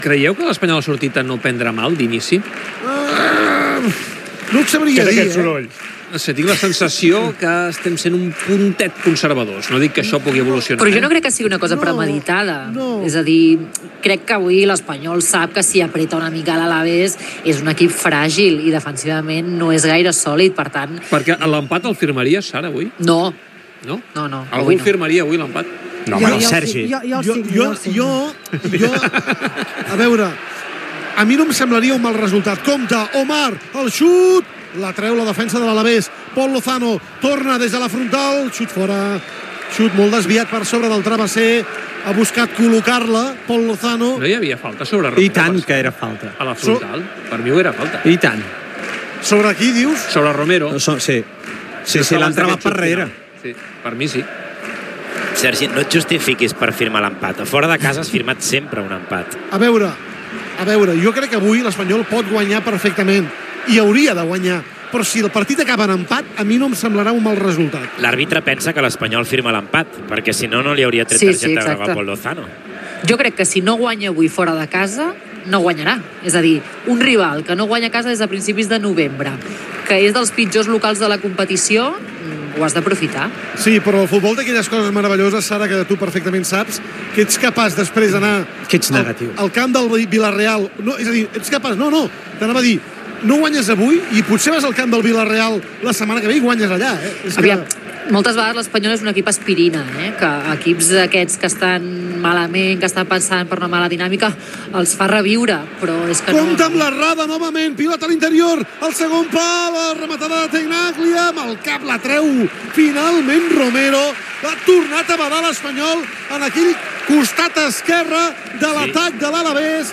Transmissió de la primera part del partit de la primera divisió masculina de futbol entre l'Alavés i el R.C.D.Espanyol Gènere radiofònic Esportiu